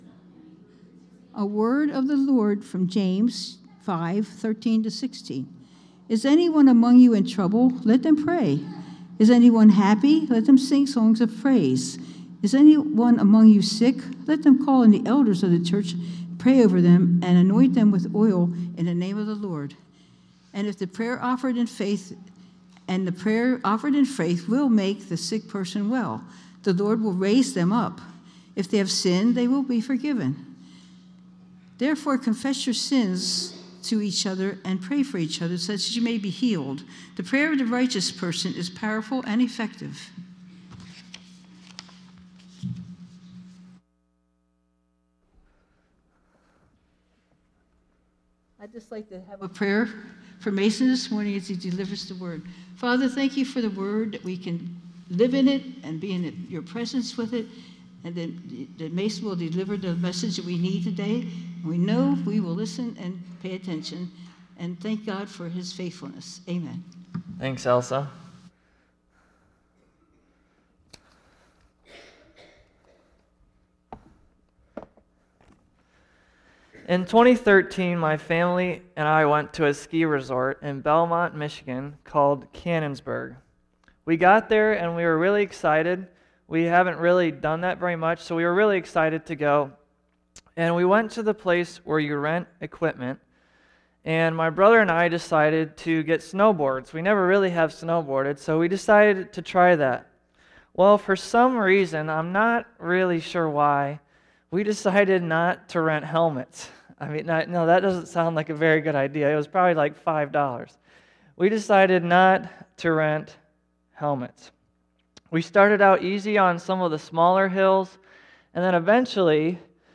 11_3_24_sunday_serrmon.mp3